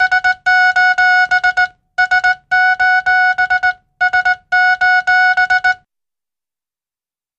Morse Code Beep - 'SOS'.